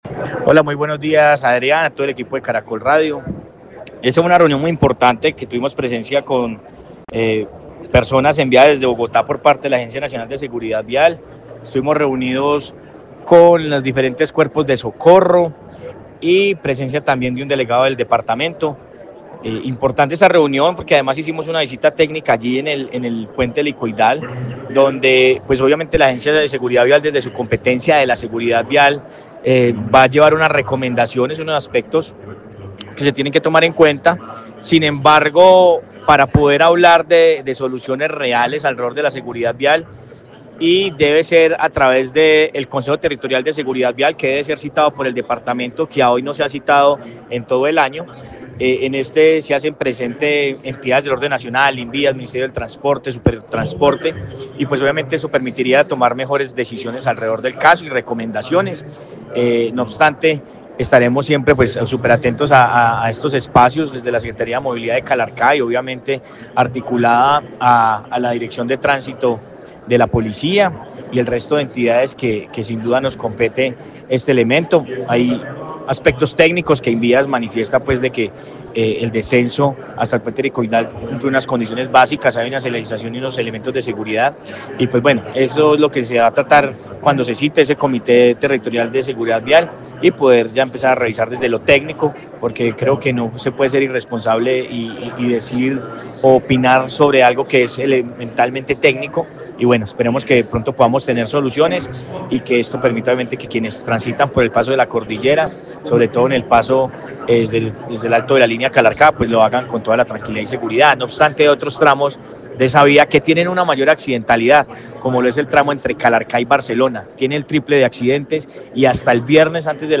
Sebastián Ramos, alcalde de Calarcá